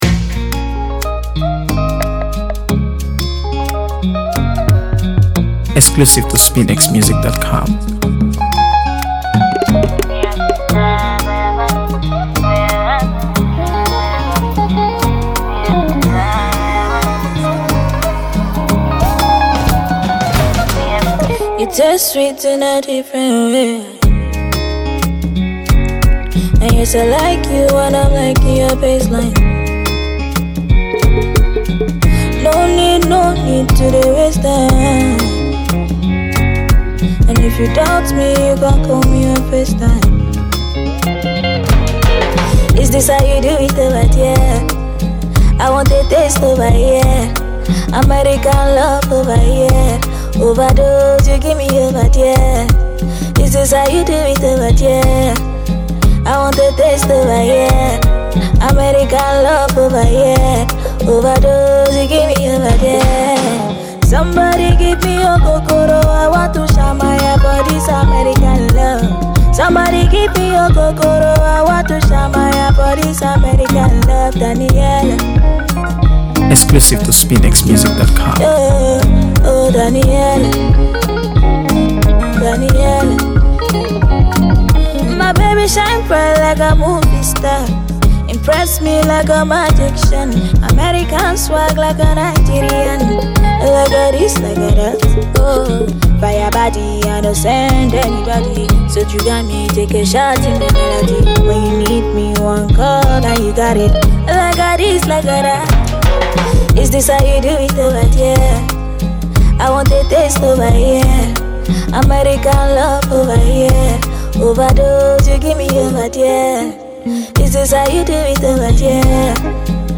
AfroBeats | AfroBeats songs
Trending Nigerian singer
guitar